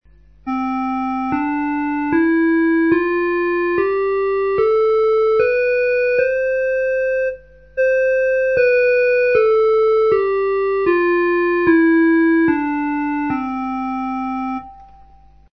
La gamme diatonique procède par tons et demi-tons : c'est celle de la musique occidentale depuis le 17è siècle, musique classique ou de variétés, que nous entendons si nous "chantons" les notes do - ré - mi - fa - sol - la - si do.